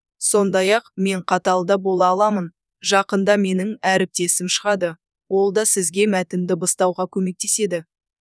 Синтез речи
Сауле говорит на казахском строго
Сауле может говорить добродушно и строго, на казахском и на русском, поэтому умеет быть разной и подстраиваться под сценарии клиента.